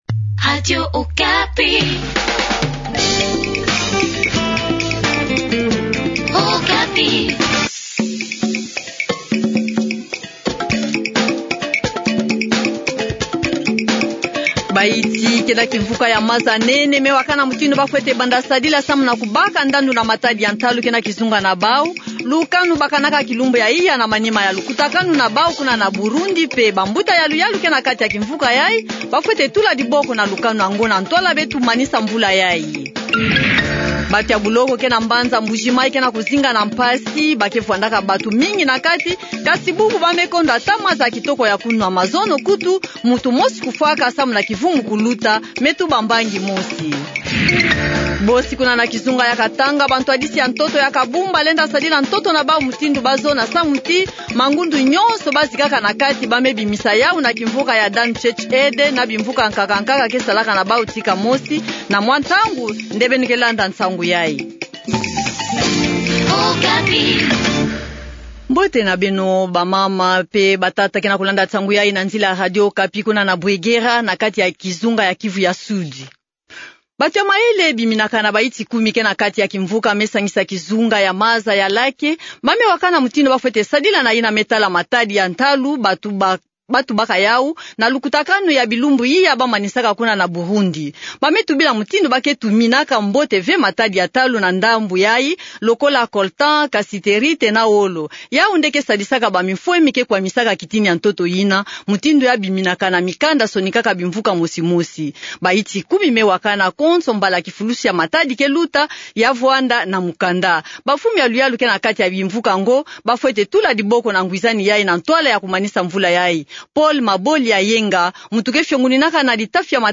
Journal kikongo du soir